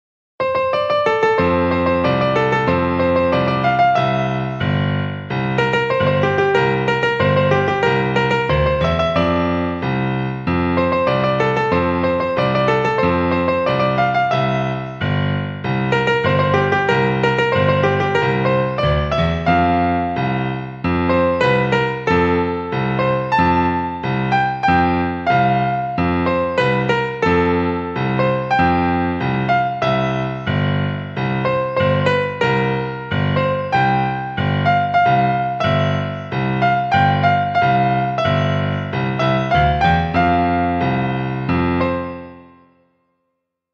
Музыка для фортепиано